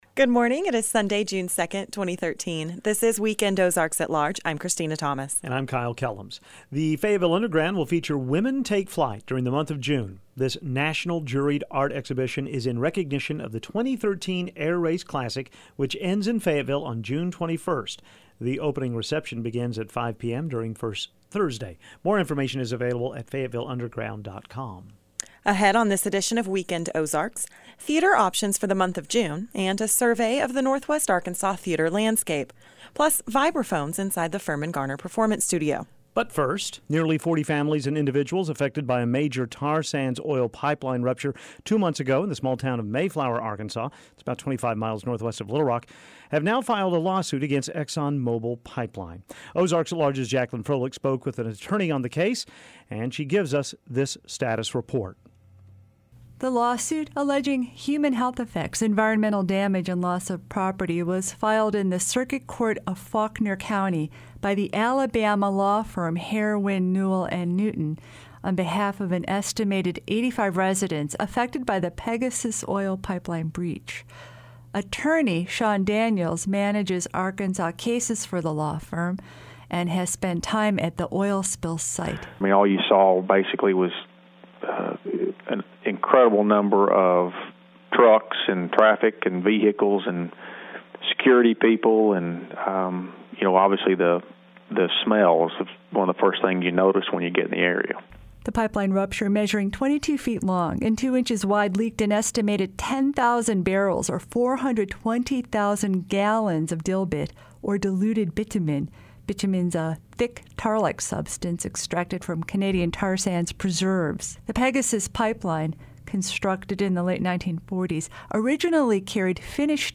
And the Adams Collins Jazz Trio performs another song in the Firmin-Garner Performance Studio.